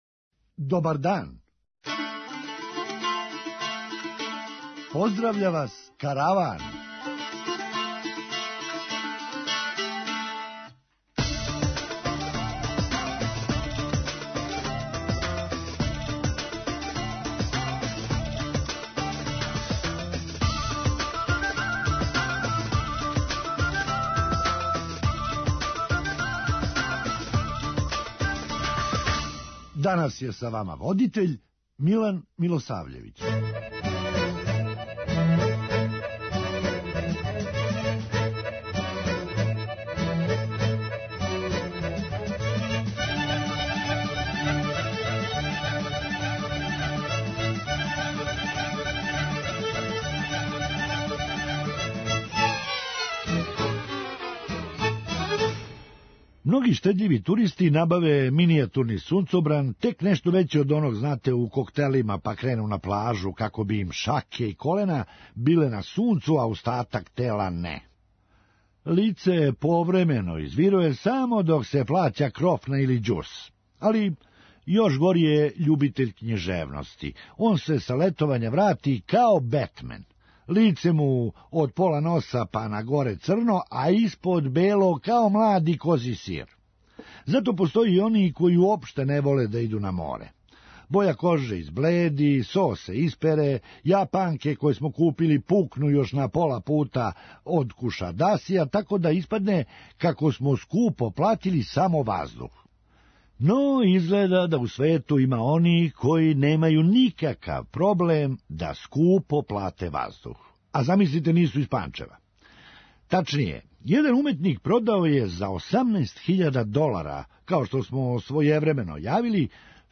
Хумористичка емисија